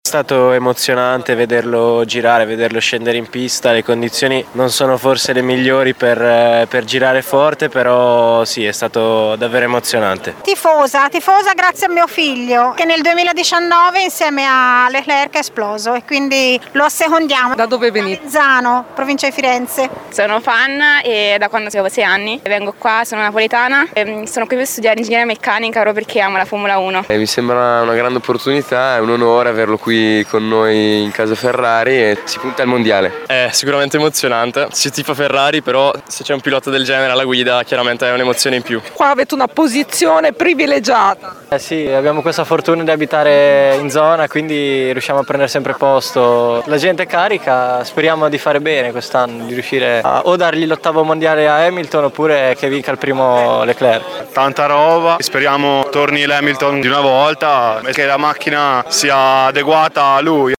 Senitamo l’entusiasmo dei tifosi Ferrari